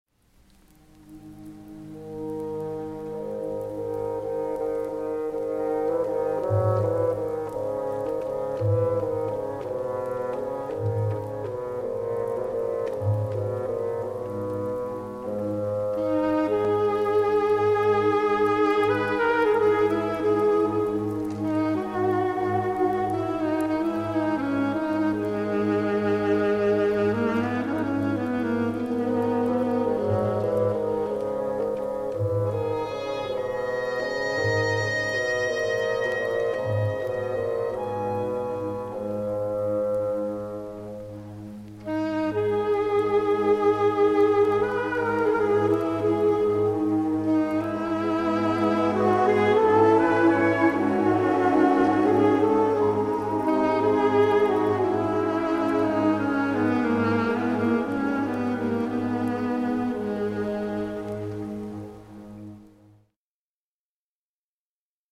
Ein Jahr später kam ein Komponist mit Namen Maurice Ravel zur Welt und fand viele Jahre später, dass die „Bilder einer Ausstellung" in einer Orchesterversion doch wesentlich ausdrucksvoller und einprägsamer seien. 1922 setzte er sich hin und bearbeitete das Werk für Orchester.